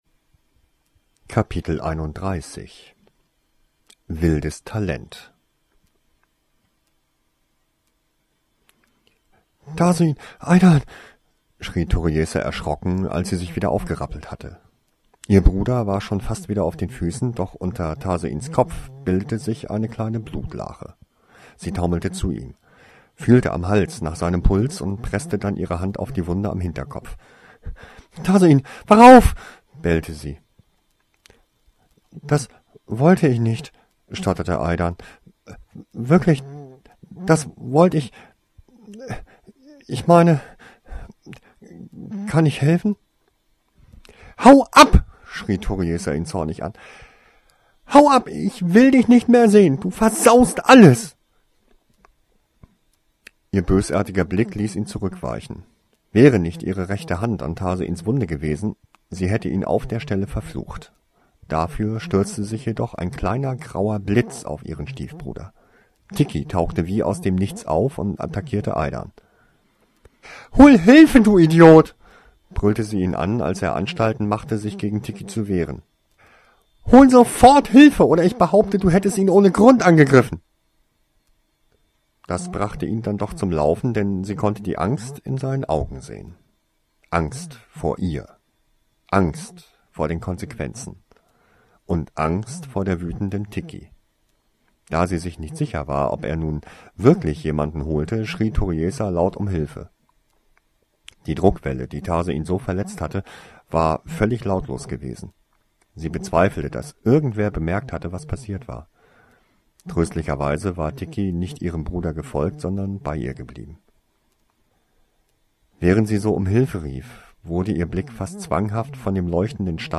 Geheimnisse der Vergangenheit [Original Hörbuch] - Abgeschlossen Podcast - Kapitel 31 | Wildes Talent | Free Listening on Podbean App